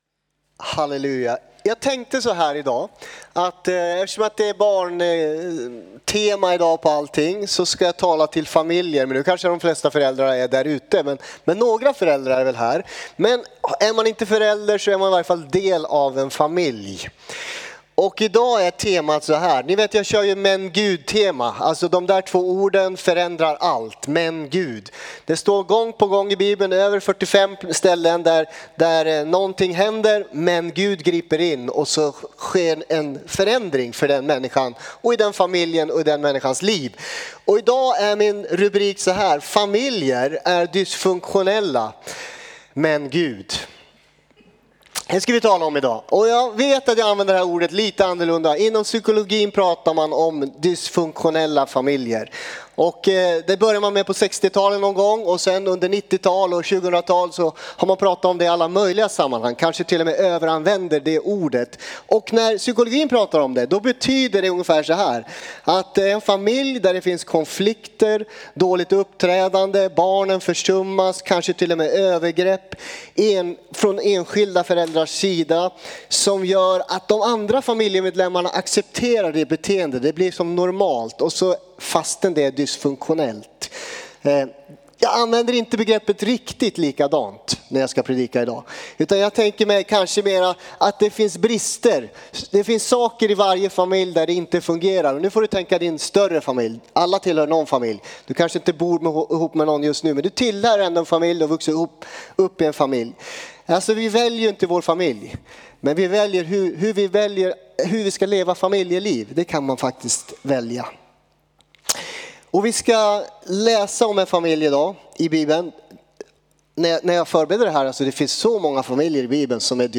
Predikan